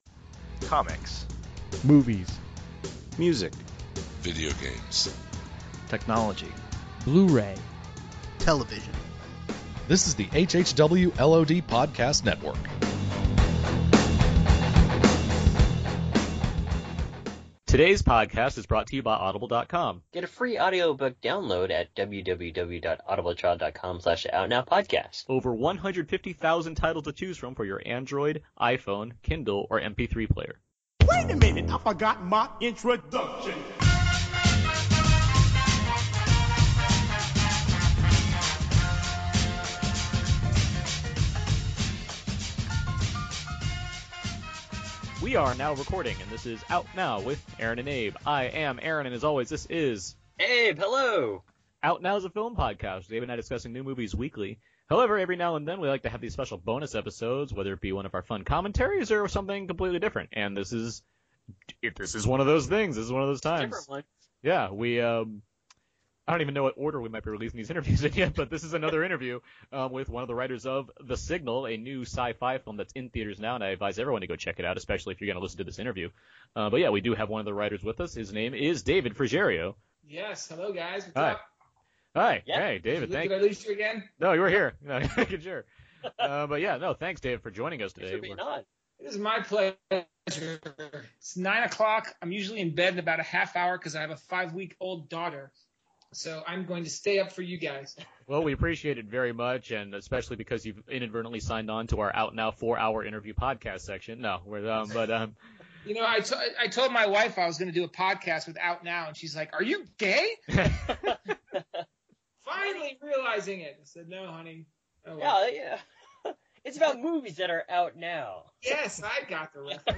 Out Now Interviews